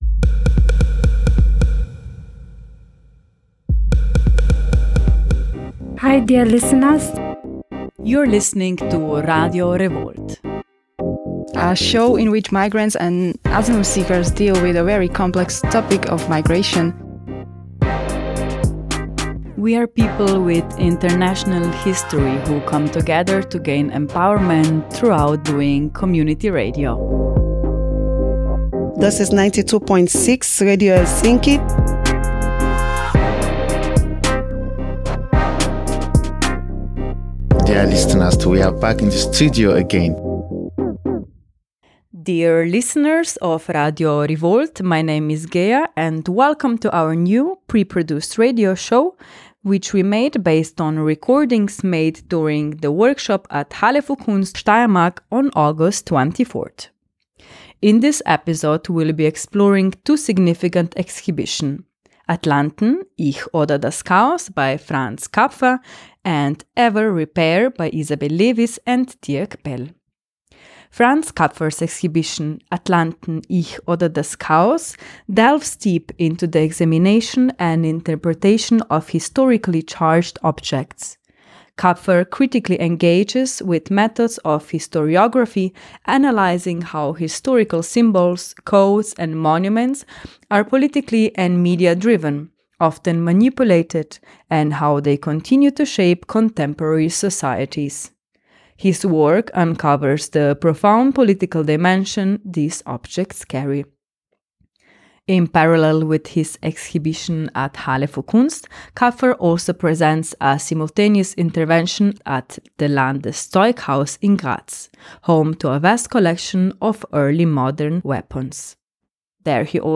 Welcome to our radio show, produced from recordings made during the workshop at HALLE FÜR KUNST on August 24, 2024.